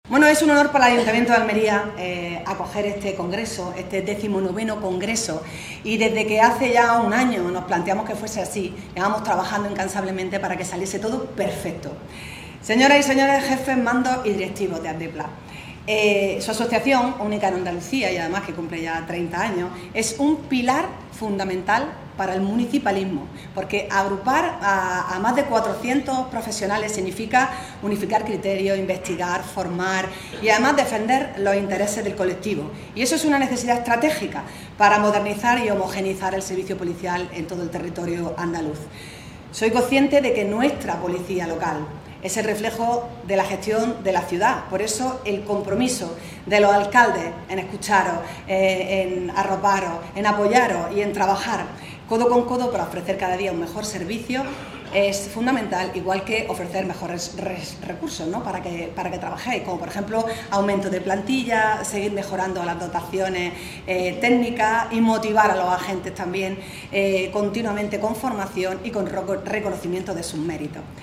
ALCALDESA-CONGRESO-TECNICO-POLICIAL.mp3